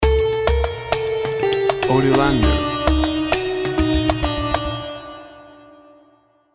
Indian musical logo with sitar, darbouka and udu sounds.
Tempo (BPM) 100